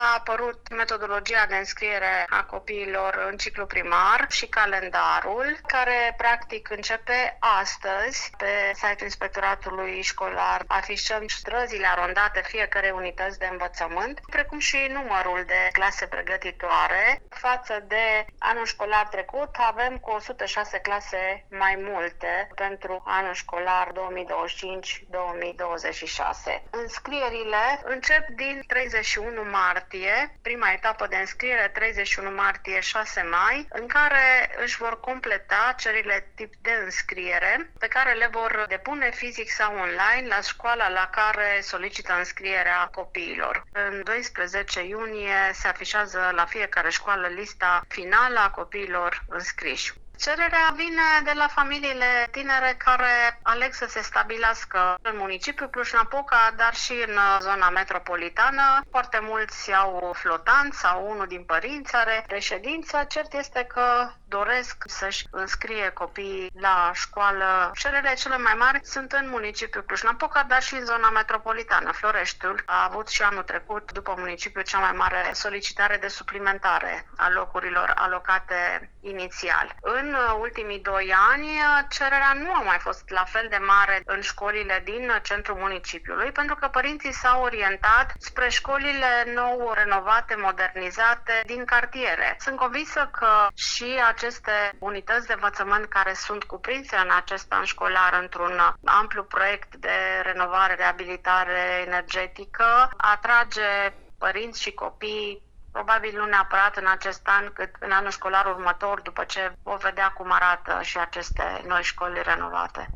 Seful ISJ Cluj, Marilena Marc, arata ca, potrivit reglementarilor, adresa de domiciliu este criteriul determinant pentru repartitia pe scoli.
Am intrebat-o pe Marilena Marc de unde vine aceasta crestere a numarului de copii.